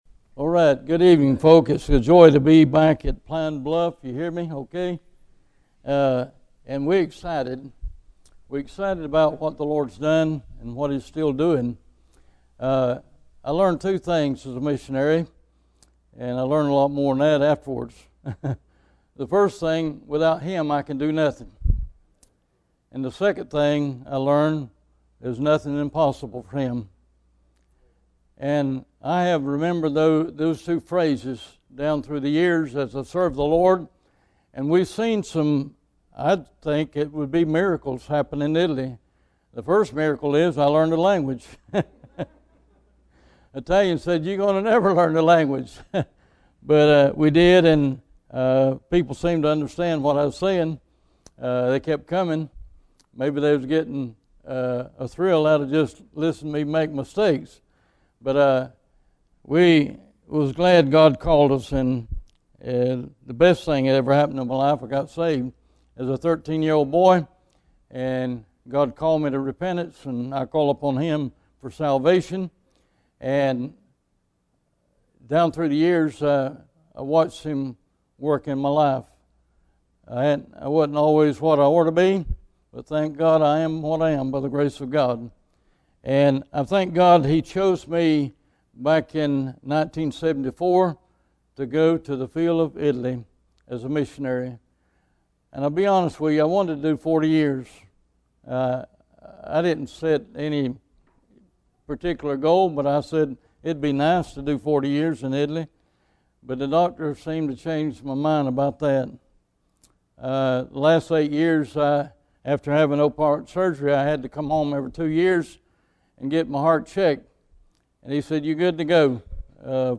Bible Text: John 12 | Preacher